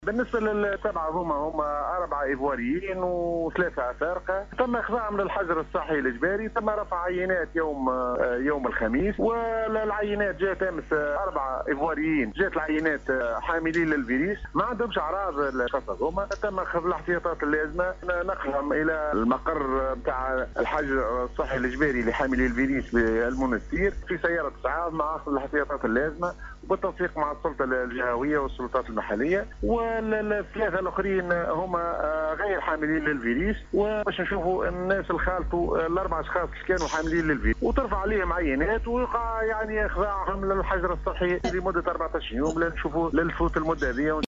اكد المدير الجهوي للصحة بالقصرين الدكتور عبد الغني الشعباني في تصريح اعلامي لراديو سيليوم اف ام ، ان الايفواريين الاربعة الذين اثبتت التحاليل اصابتهم بفيروس كورونا ، تم وضعهم في الحجر الصحي بدار الشباب القصرين في مرحلة اولى ، ثم تم تحويلهم مساء امس الجمعة 19 جوان 2020 بواسطة سيارة إسعاف الى مركز  covid-19  بالمنستير لتلقي العلاج.